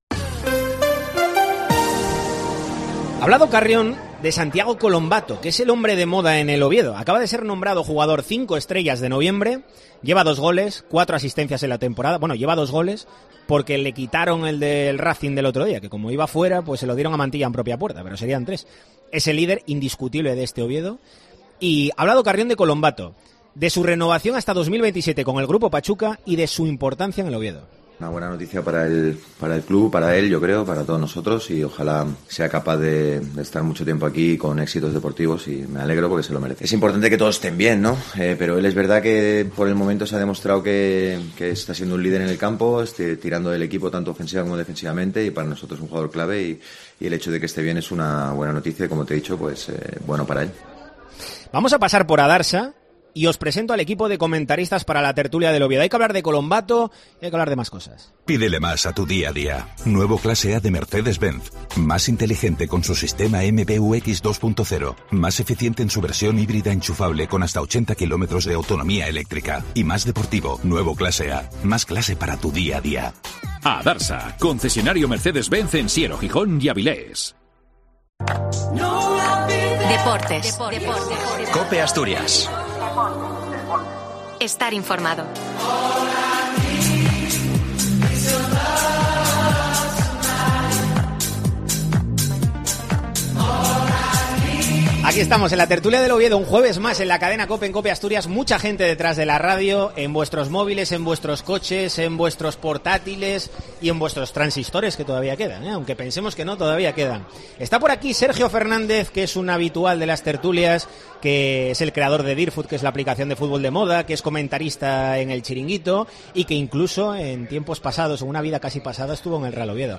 'La Tertulia del Oviedo' en Deportes COPE Asturias En el capitulo de este jueves en Deportes COPE Asturias , debatimos en 'La Tertulia del Oviedo' desde la sidrería La Espita acerca de la política de fichajes del Grupo Pachuca . ¿Aprueban o suspenden los dueños del Oviedo en las contrataciones desde su llegada?